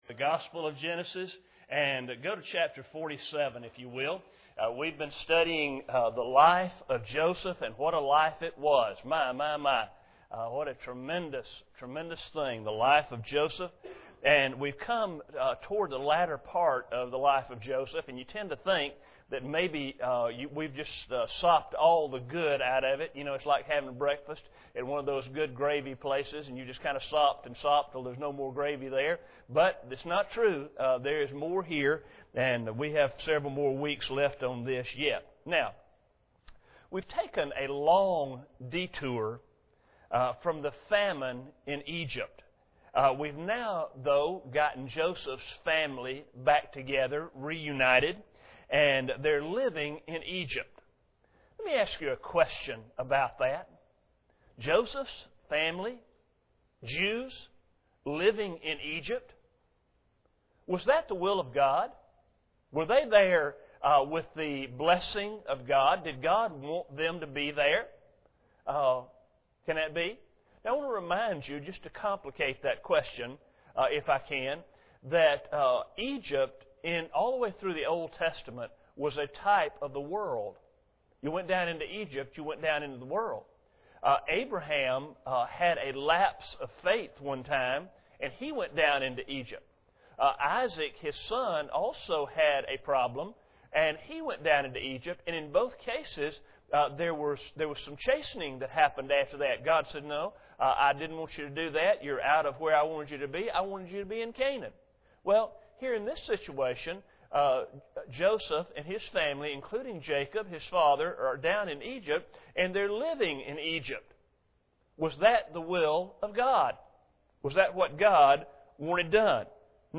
Genesis 46:13-26 Service Type: Sunday Evening Bible Text